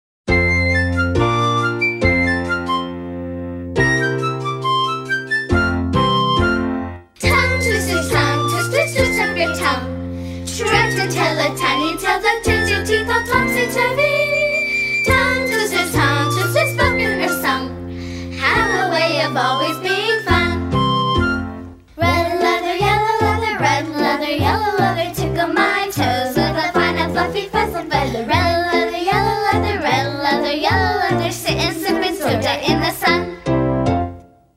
▪ The full-length music track with vocals.
Listen to a sample of this song.